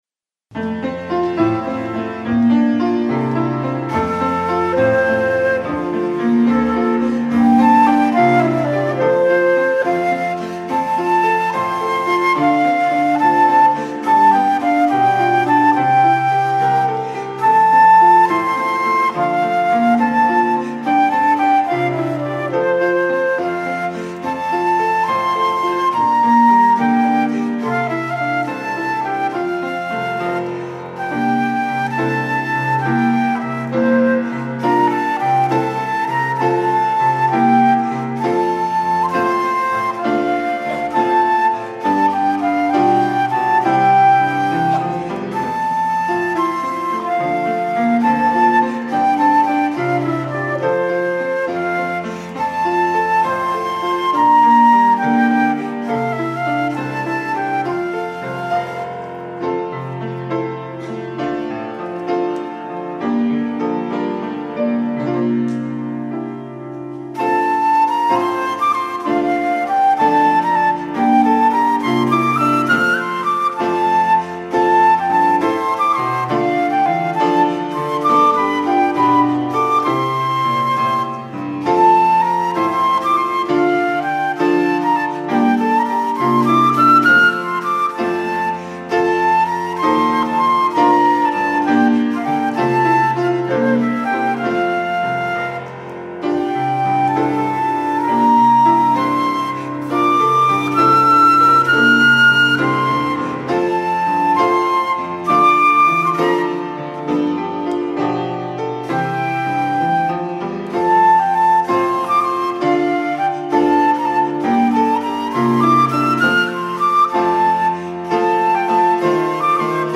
악기 연주